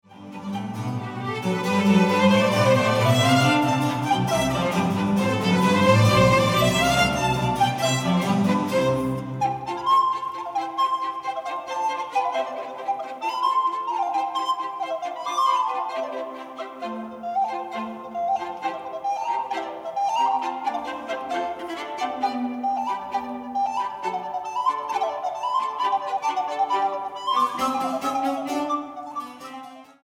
para flauta, cuerdas y continuo